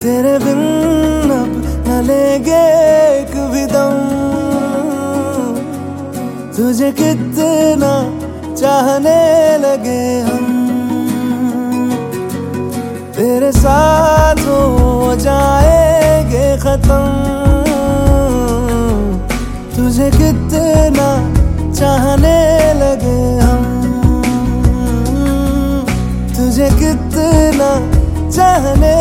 Sad Ringtones